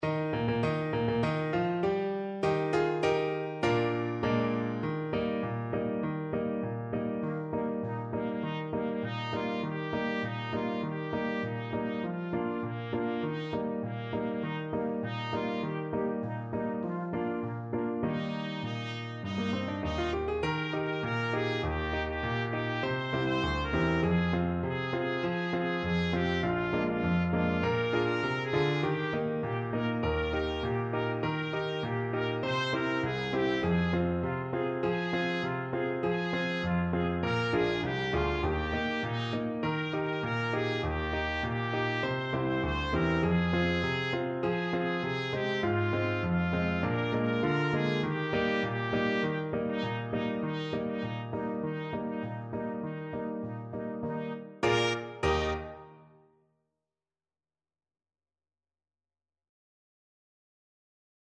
Trumpet
Traditional Music of unknown author.
Eb major (Sounding Pitch) F major (Trumpet in Bb) (View more Eb major Music for Trumpet )
2/4 (View more 2/4 Music)
With energy =c.100
Classical (View more Classical Trumpet Music)
Mexican